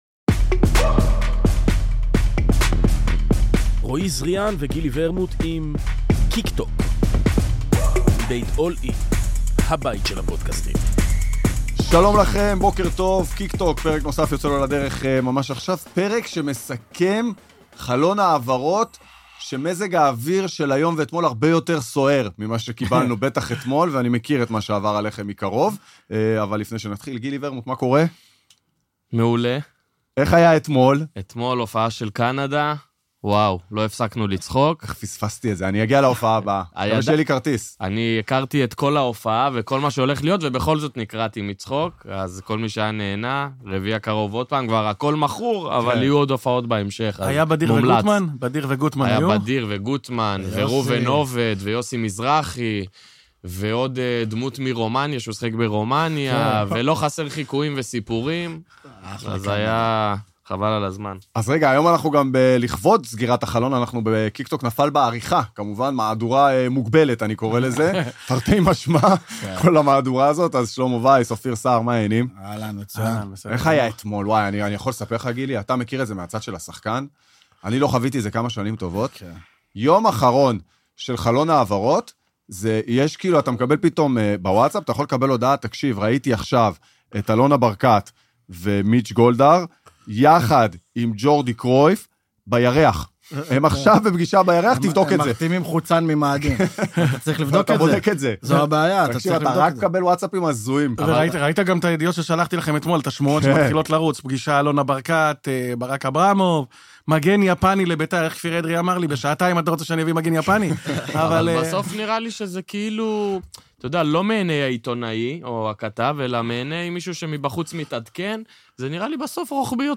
אורחים באולפן